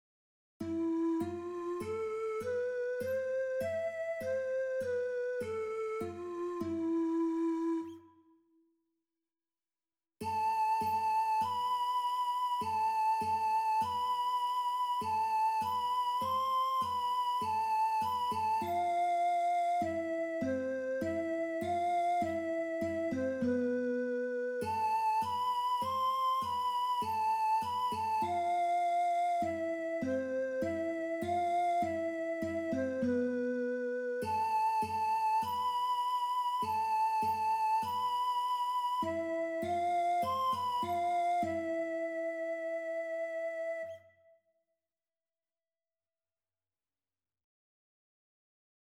Scale png - Pentatonic
Japanese Scale and Sakura.mp3